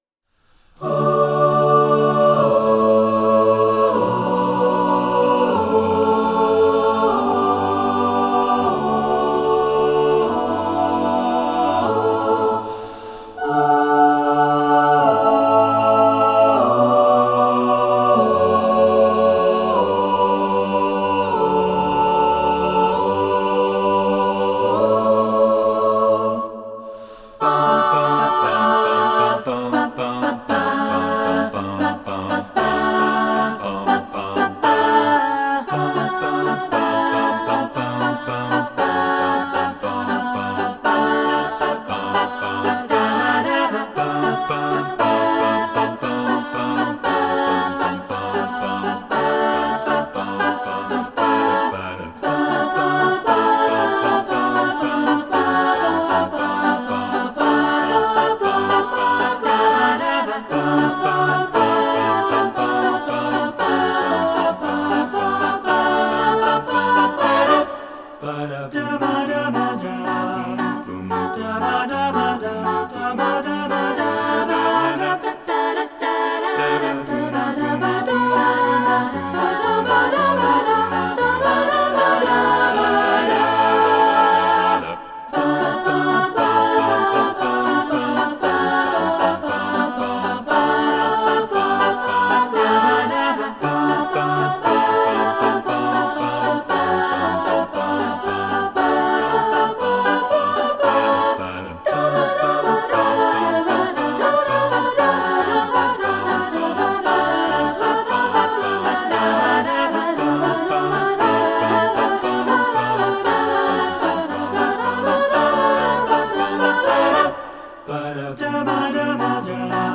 Instrumentation: choir (SSATB, a cappella)
secular choral
- SSATB, a cappella (SATB recording), sample